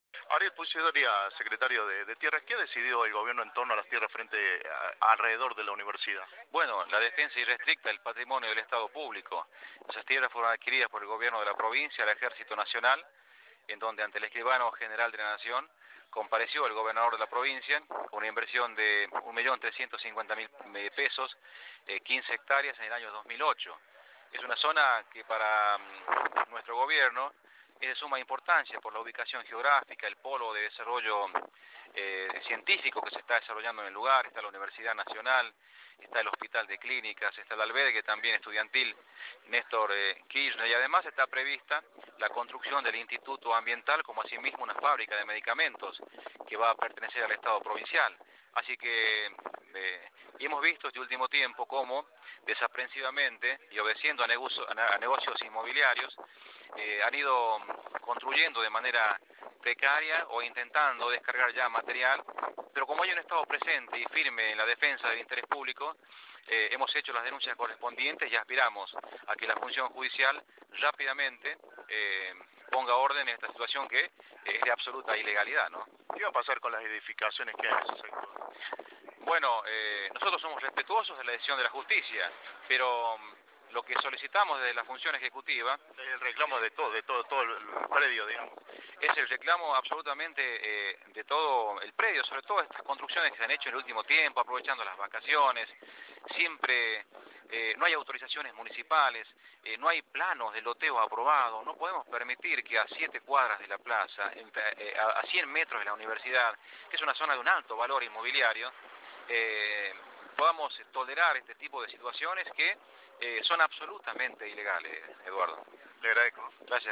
Ariel Puy Soria, secretario de Tierras, por Cadena 3
ariel-puy-soria-secretario-de-tierras-por-cadena-3.mp3